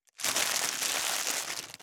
612コンビニ袋,ゴミ袋,スーパーの袋,袋,買い出しの音,ゴミ出しの音,袋を運ぶ音,
効果音